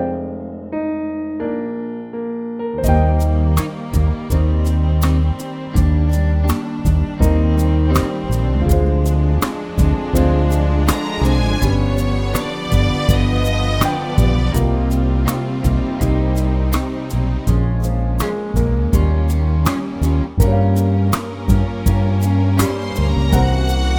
Live Version Crooners 2:48 Buy £1.50